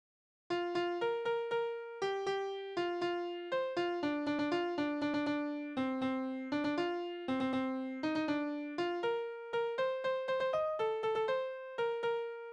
Balladen: Edelmann und Höriger
Tonart: B-Dur
Taktart: 3/8
Tonumfang: kleine Dezime
Besetzung: vokal